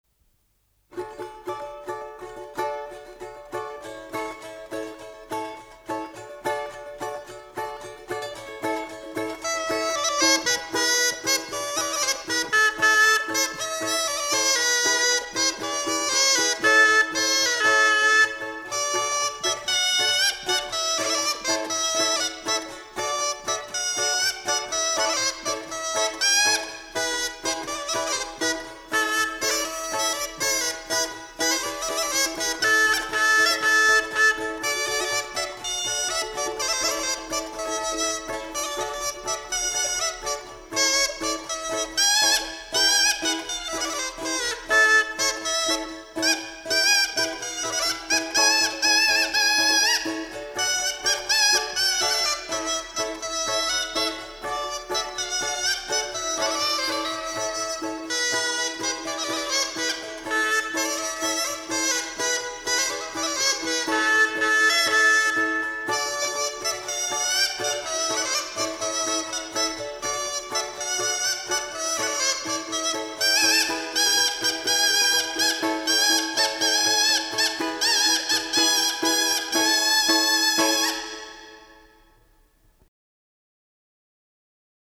Голоса уходящего века (Курское село Илёк) Светит месяц (балалайки, рожок, инструментальная версия)